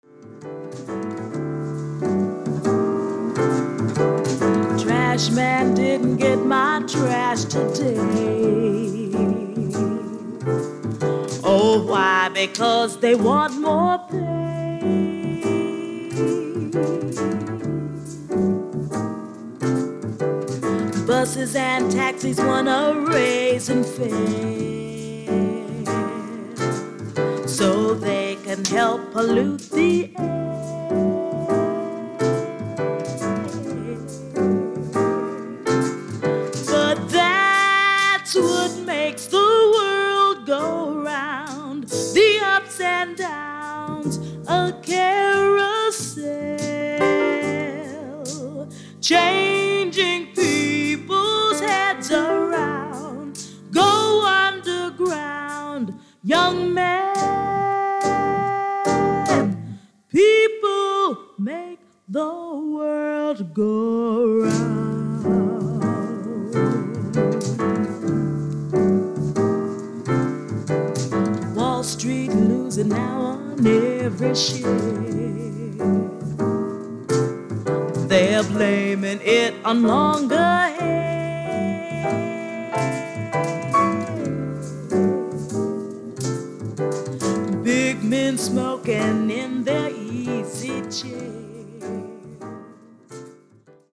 Drums, Bells
Piano, Electric Piano
Trumpet, Kalimba
Vocals
Saxophone, Cowbell